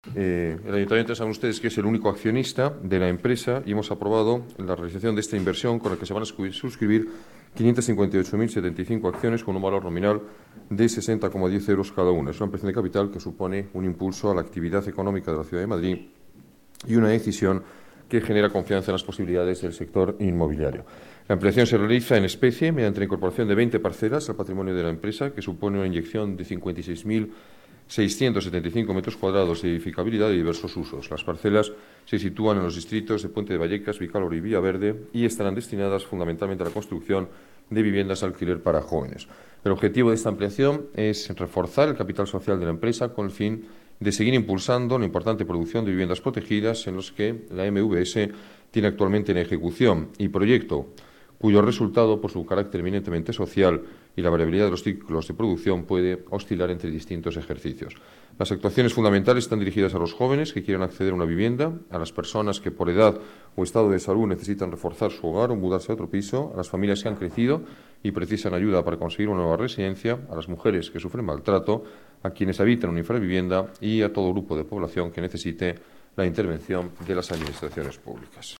Nueva ventana:Declaraciones del alcalde de Madrid, Alberto Ruiz-Gallardón: ampliación de capital de la EMVS